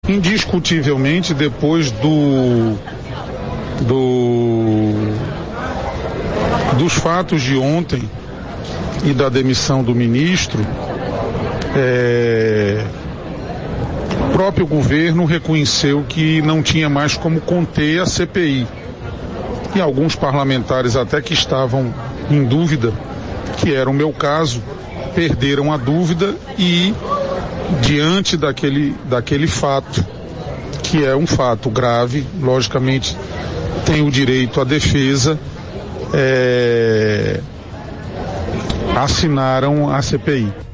As declarações dos dois parlamentares repercutiram em entrevista à Arapuan FM.